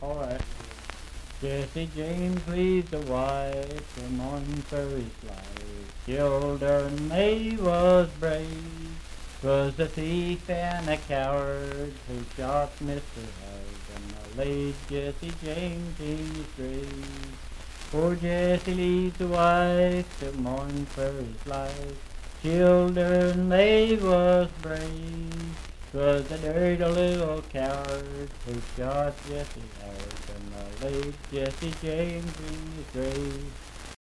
Unaccompanied vocal music
in Riverton, Pendleton County, WV.
Verse-refrain 1(4).
Voice (sung)